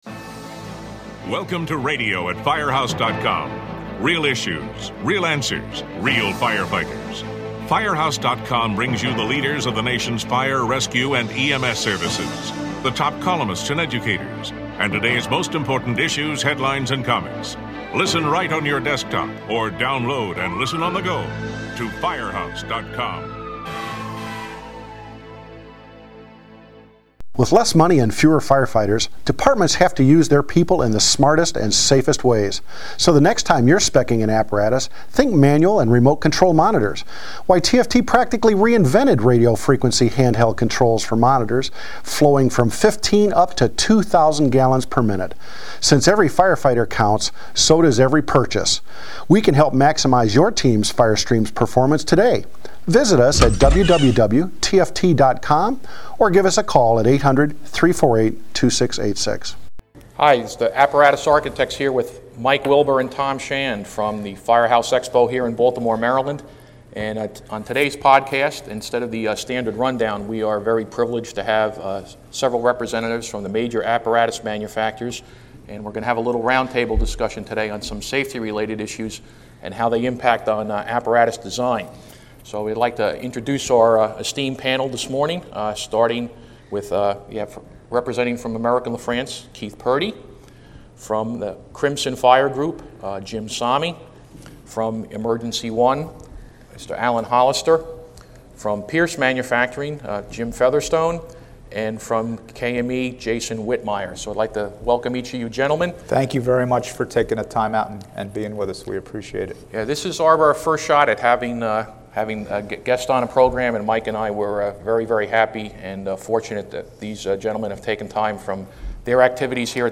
This roundtable focuses on firefighter safety in all aspects of the apparatus design and use.